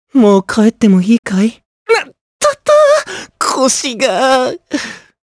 Evan-Vox_Victory_jp_b.wav